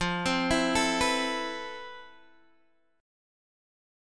FM#11 chord